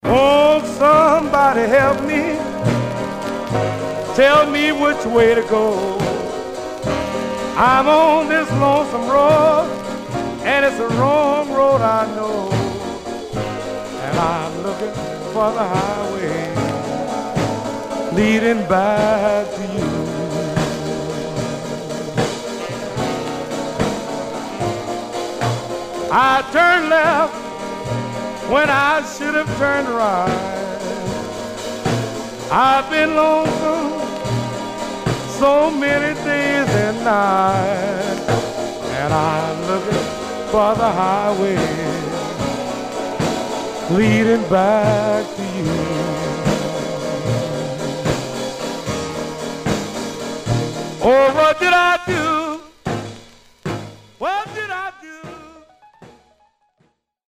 Some surface noise/wear Stereo/mono Mono
R&B Instrumental